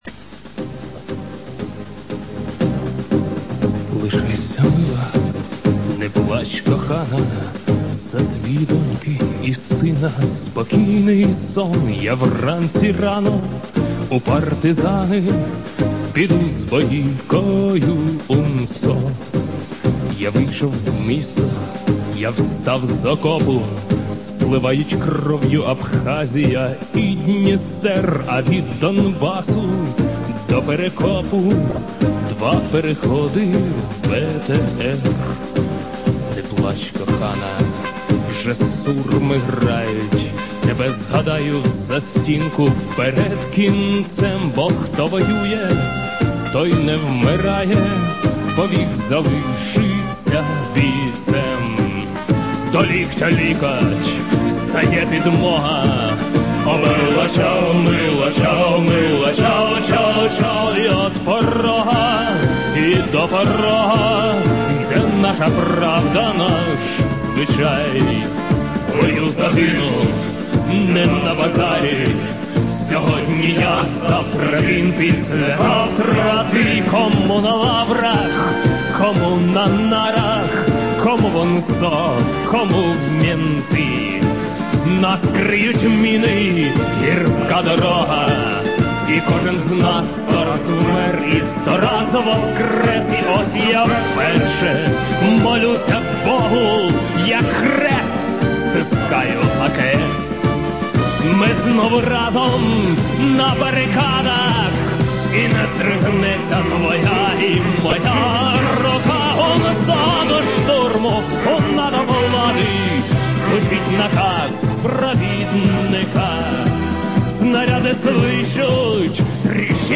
Звучит боевая песня УНА-УНСО (скачать)